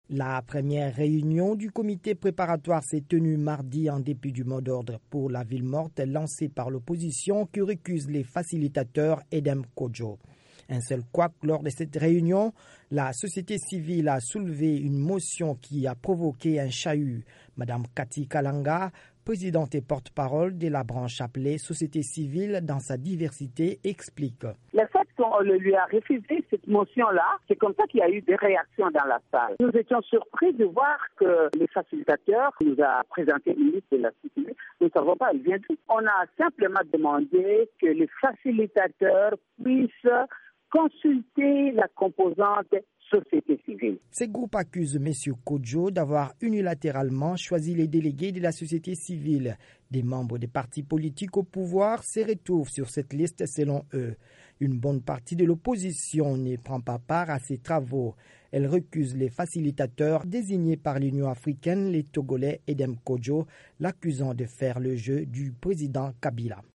by VOA Afrique